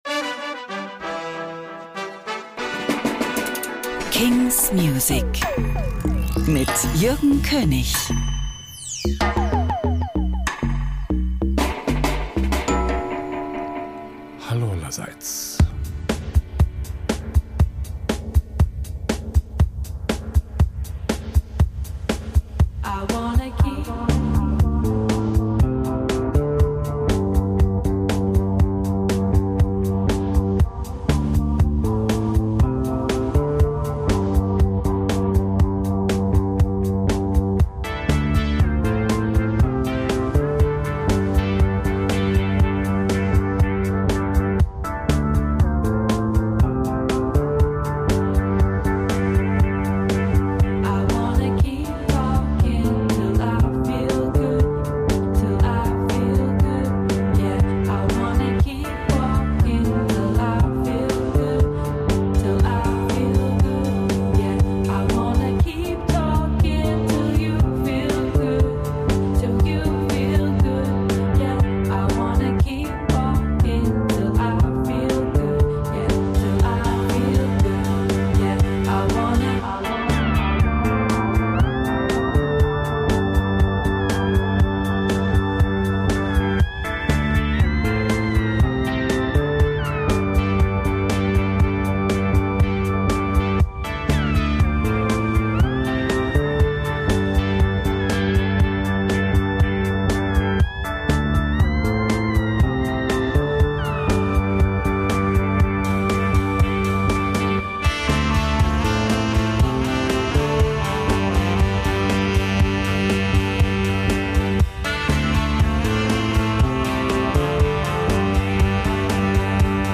weekly indie-tipps for all fans of cool sounds and finest pop culture.